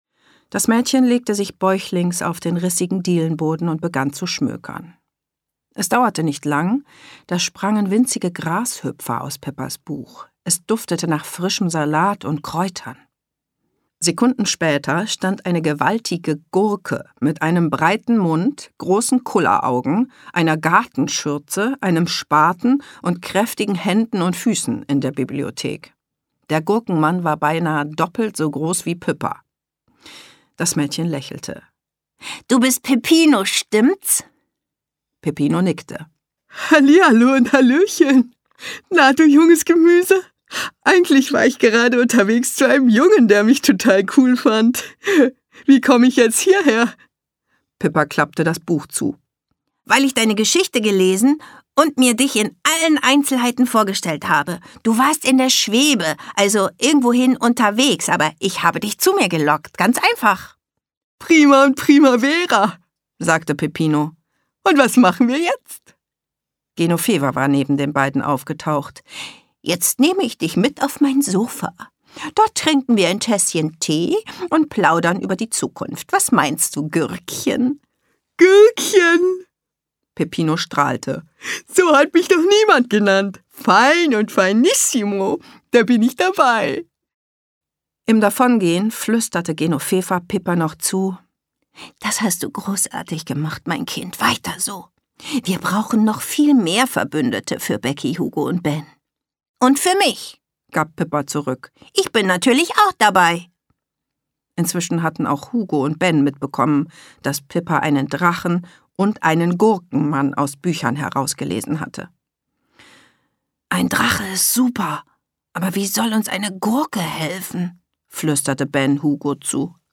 Schlagworte Abenteuer • Bücherschloss • Einschlafen • Fantasy • Feen • Freundschaft • Hörbuch • Kinder • Magie • Zauber